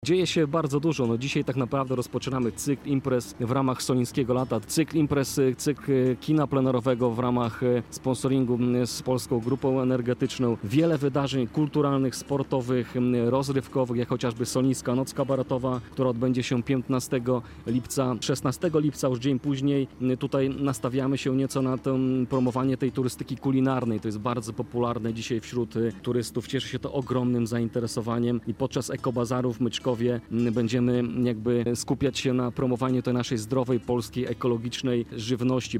Kamper Radia Biwak zaparkował w Polańczyku.